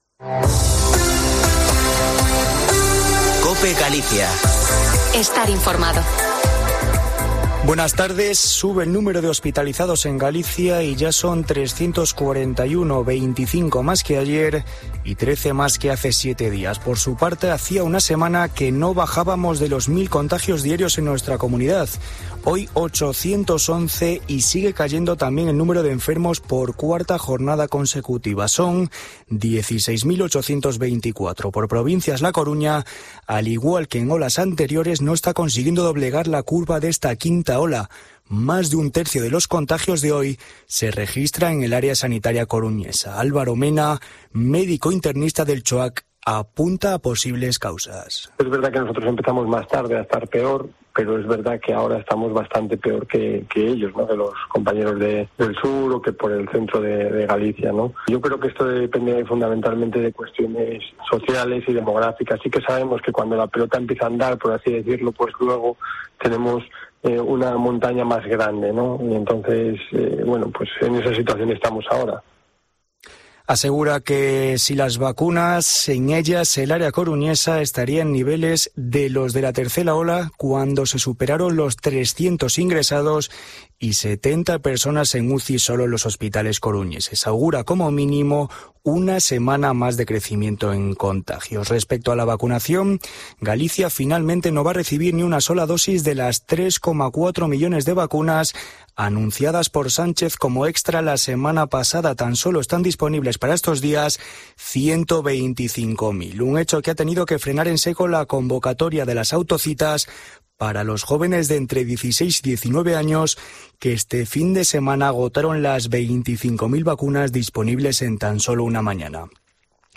Informativo Mediodía en Cope Galicia 09/08/2021. De 14.48 a 14.58h